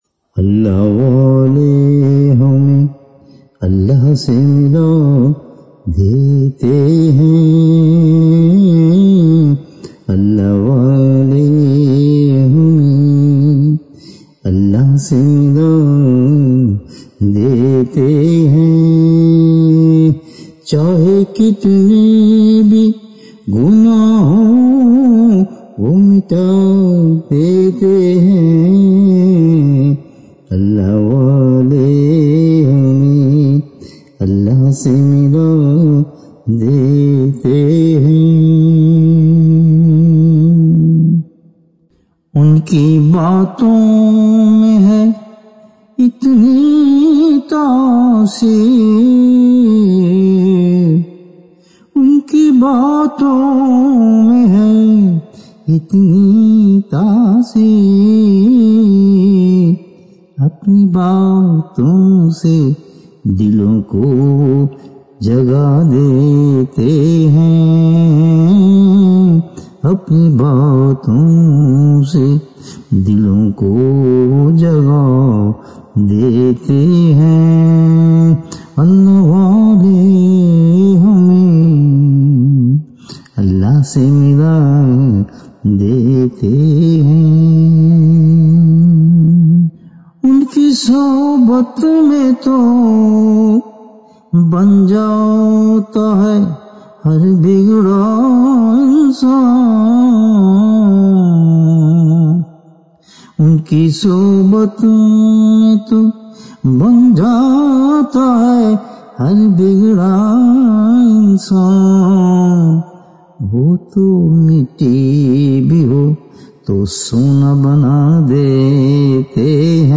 SubhanAllah as always beautiful Asha'r and out of this world voice and recitation, may Allah SWT give Hadhrat Saab fantastic health and long life inshAlah